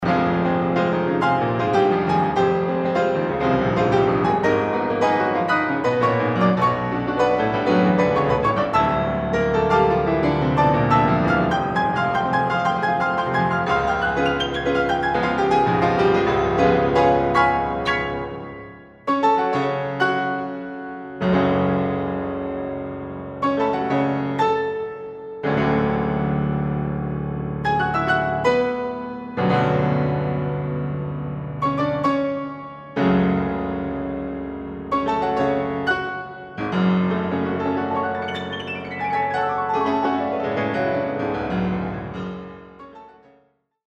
piano arrangement